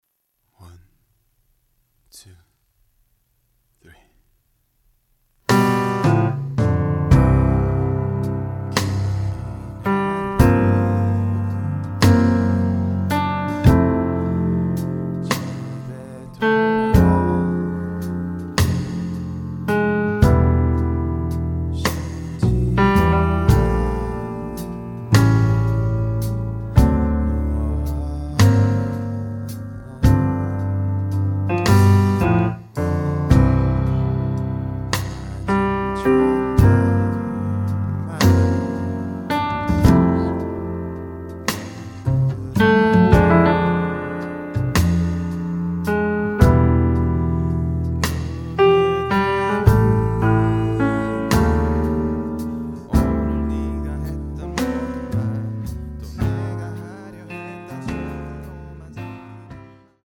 음정 원키 4:07
장르 가요 구분 Voice Cut